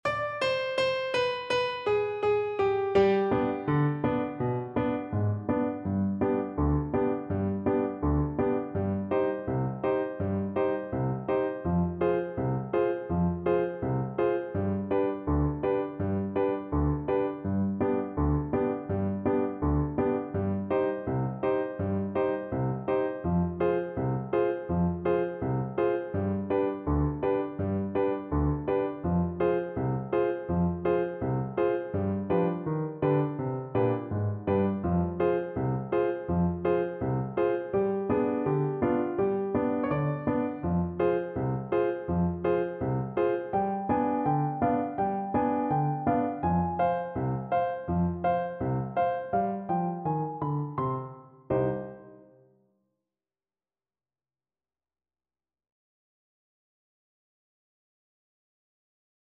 Clarinet
C minor (Sounding Pitch) D minor (Clarinet in Bb) (View more C minor Music for Clarinet )
Allegro (View more music marked Allegro)
Traditional (View more Traditional Clarinet Music)